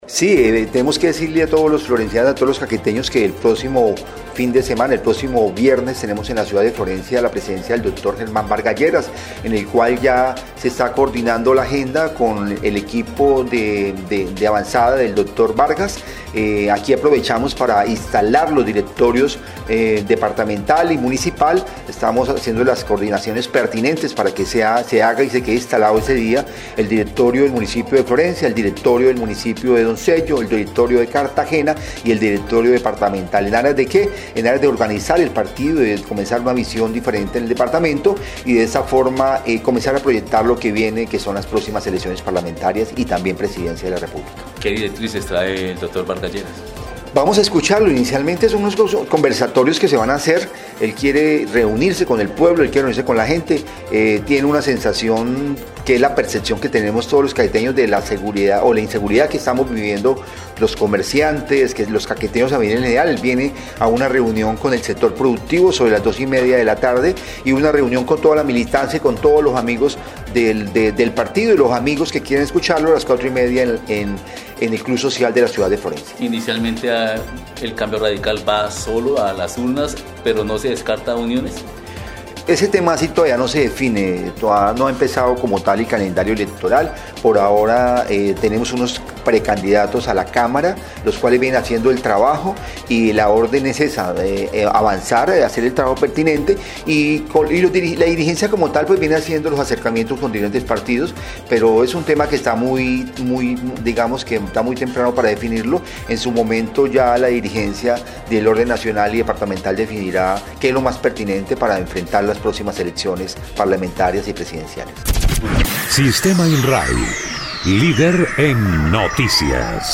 Así lo dio a conocer el diputado por Cambio Radical, Richard Gutiérrez Cruz.
04_DIPUTADO_RICHARD_GUTIERREZ_VISITA.mp3